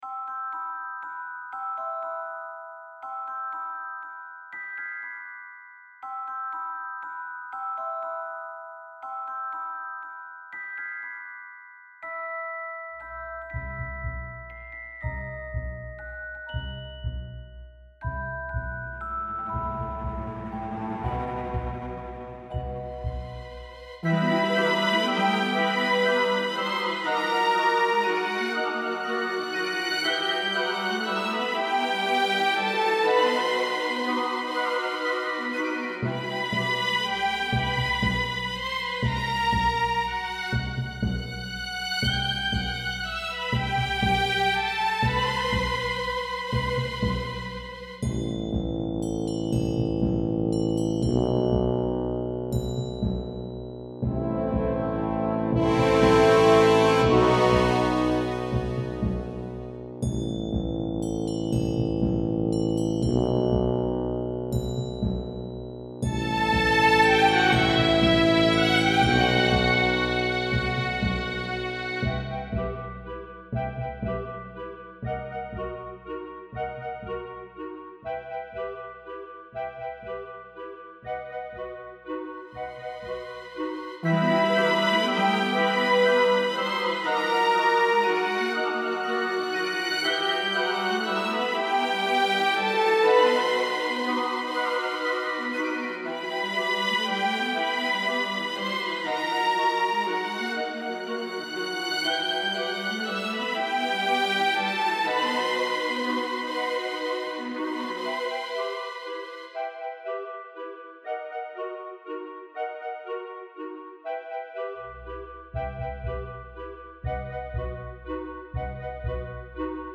My first attempt at a waltz.